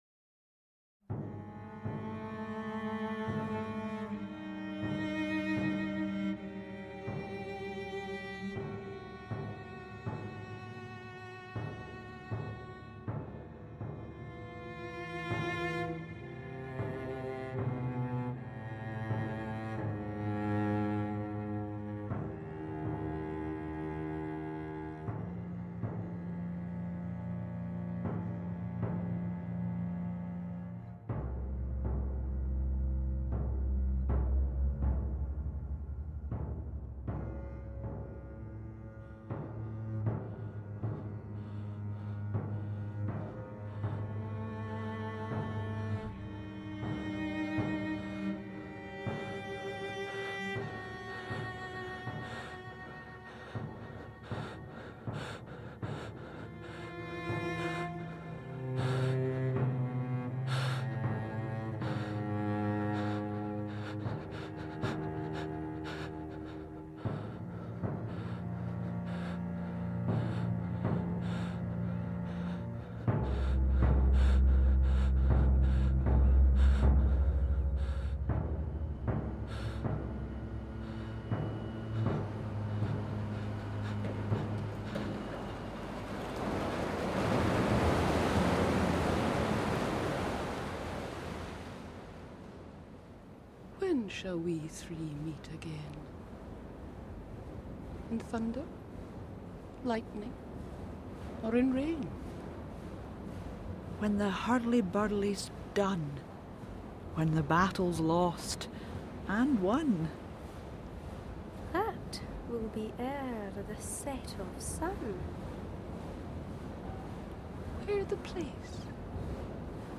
We are as much in the twenty-first century as in medieval Scotland – the tensions, the politics, the struggle for power and dark ambition is part of our lives… This is also reflected in the sound world, with modern machinery and tanks. Macbeth is part of Naxos AudioBooks’ exciting new series of complete dramatisations of the works of Shakespeare, in conjunction with Cambridge University Press.